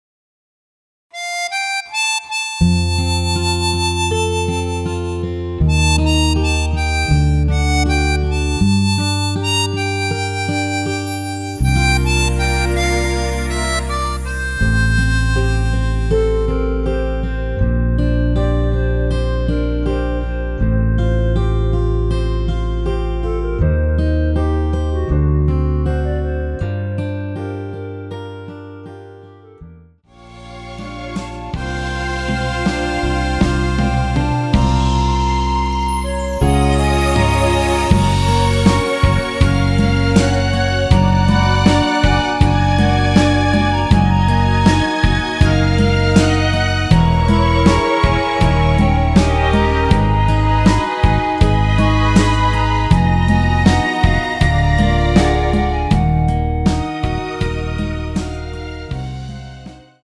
원키에서(+4)올린 멜로디 포함된 MR입니다.(미리듣기 확인)
앞부분30초, 뒷부분30초씩 편집해서 올려 드리고 있습니다.
중간에 음이 끈어지고 다시 나오는 이유는